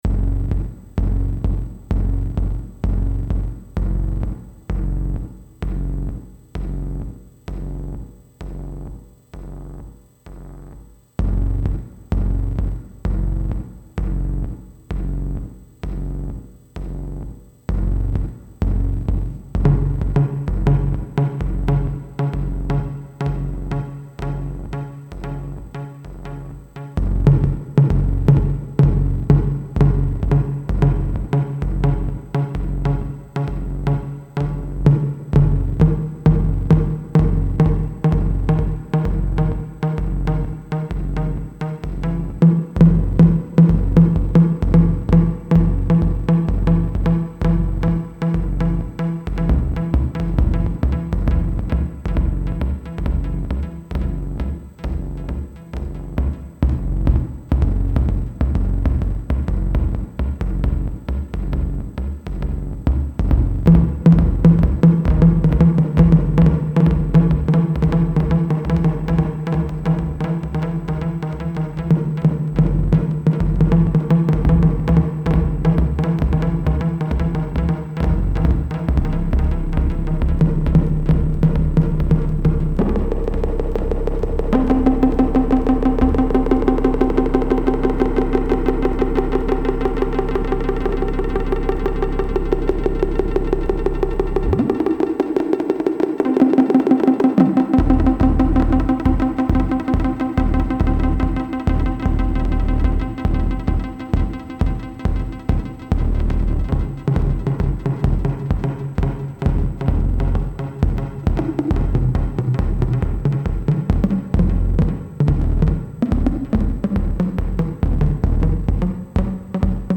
A rhythmic beat pulse which can be activated by a light sensor is created.
Low frequencies remind of monotonous techno beats. High frequencies resemble repetitive elements of plucked string instruments.